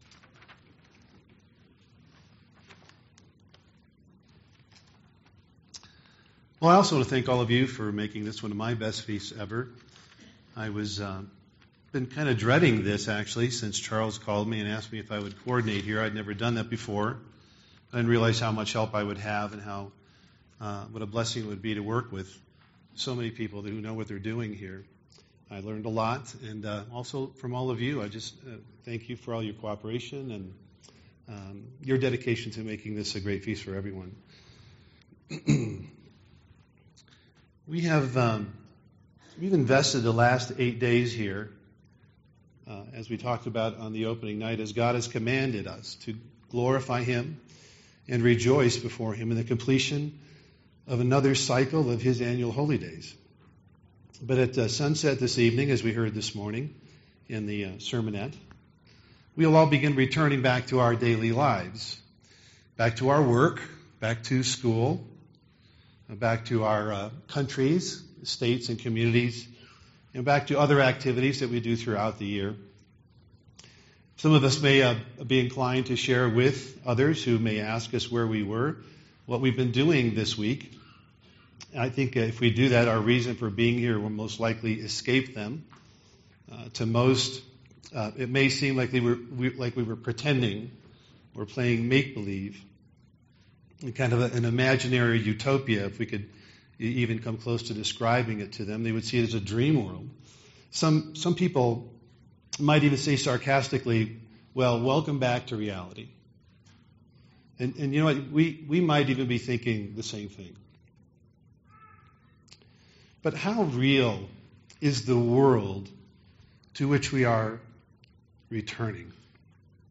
This sermon was given at the Steamboat Springs, Colorado 2017 Feast site.